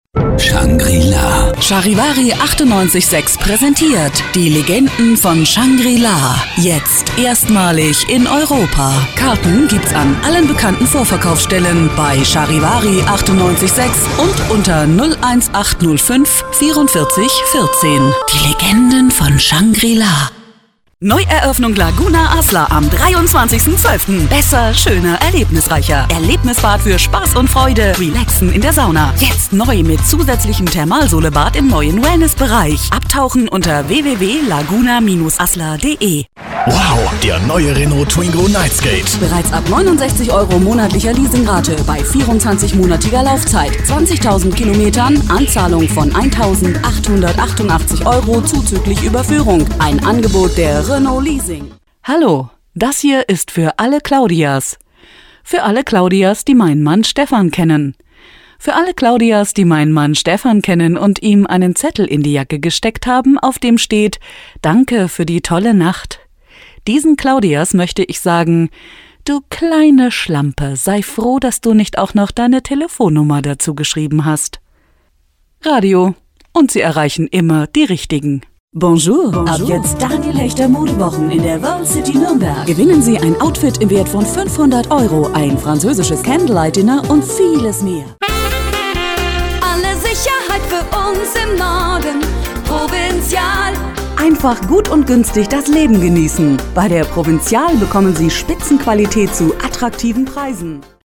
Sprechprobe: Werbung (Muttersprache):
Female german voices artist.